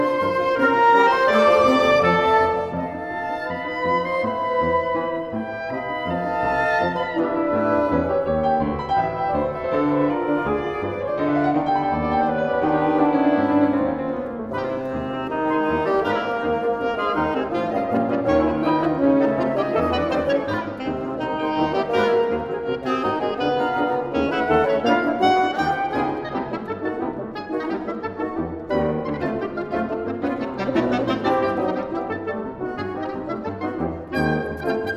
Жанр: Классика
Chamber Music